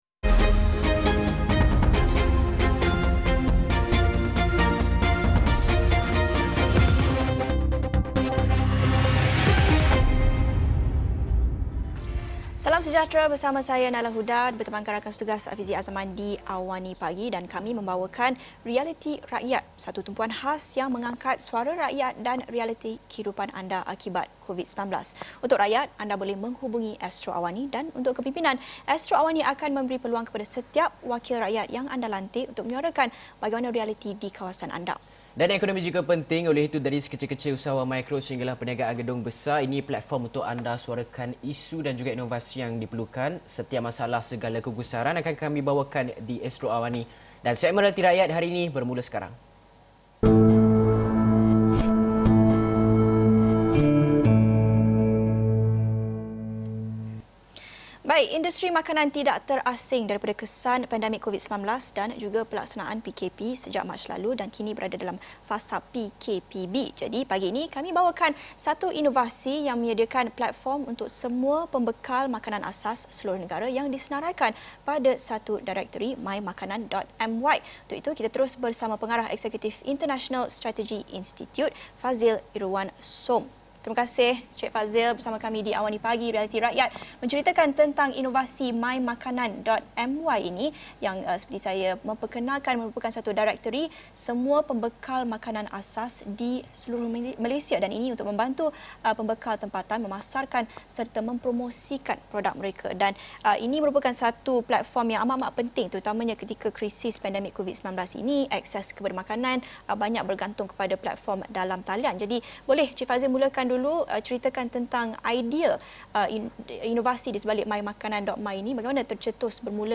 Episod ini telah disiarkan secara langsung dalam program AWANI Pagi, di saluran 501, jam 8:30 pagi.